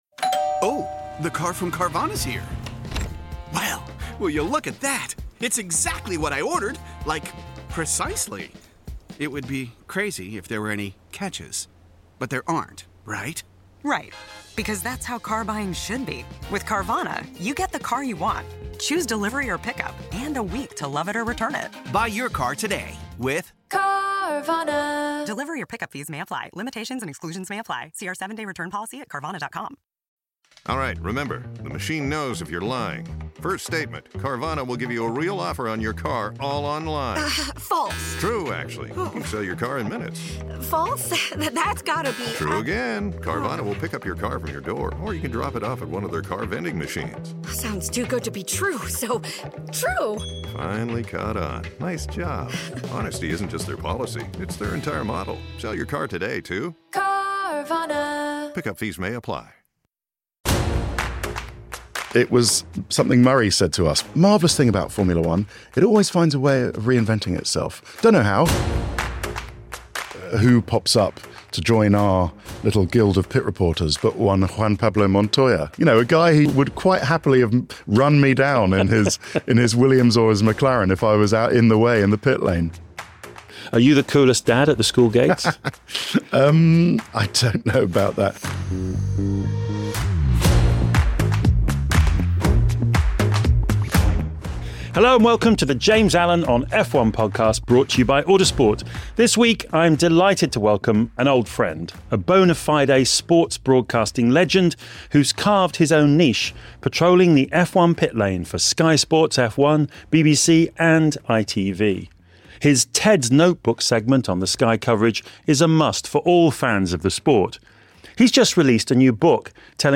James Allen, who mentored Ted early in his TV career, chats to him about the F1 personalities that have stood out, moments of high drama that he found himself in the middle of and the toughest moments he’s faced. Ted lifts the veil on how live F1 TV coverage works behind the scenes.